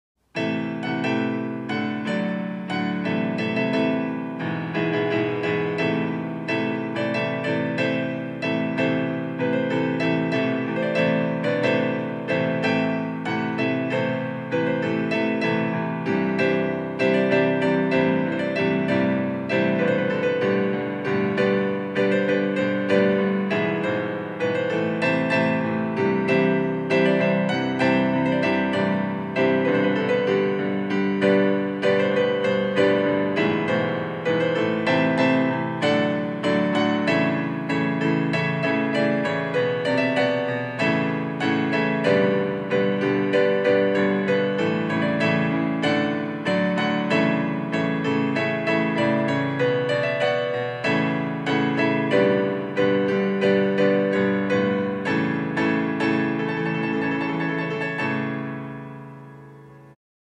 на фортепиано